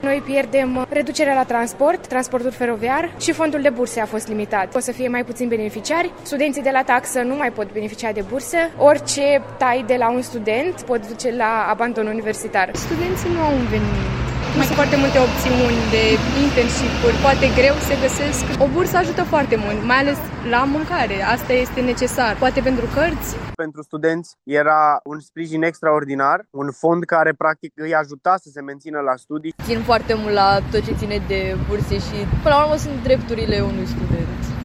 Insert-Vox-29-09-studenti.wav